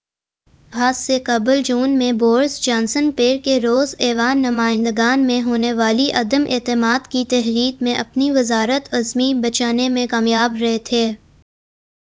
Spoofed_TTS/Speaker_01/260.wav · CSALT/deepfake_detection_dataset_urdu at main